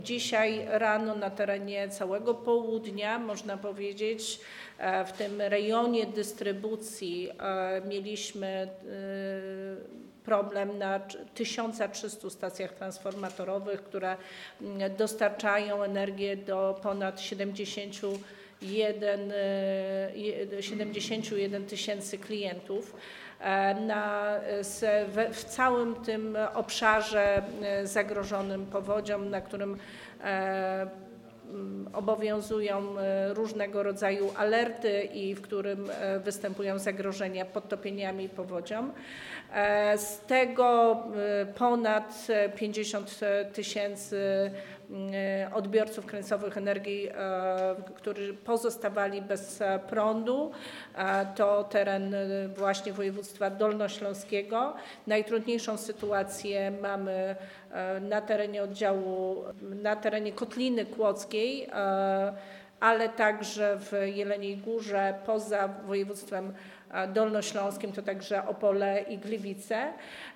We Wrocławiu obecna jest też minister Klimatu i Środowiska – Paulina Hennig – Kloska, która zapewniła, że odpowiednie ministerstwa cały czas monitorują sytuację jaka panuje na obszarze zagrożonym.